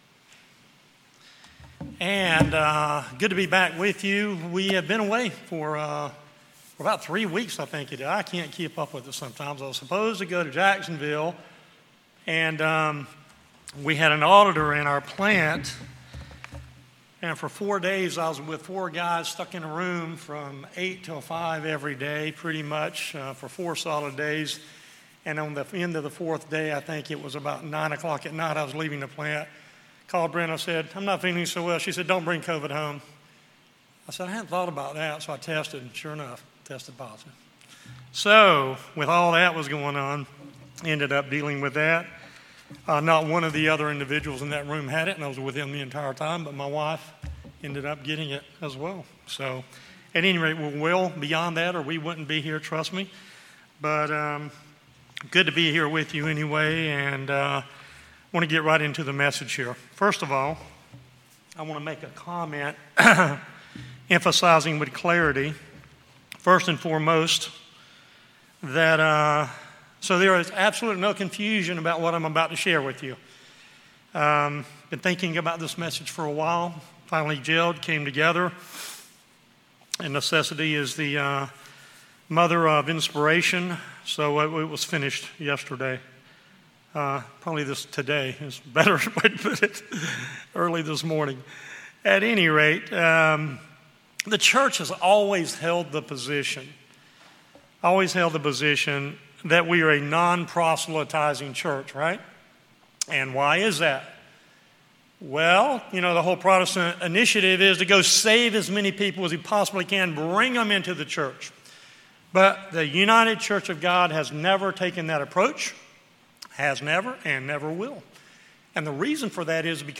Sermons
Given in Raleigh, NC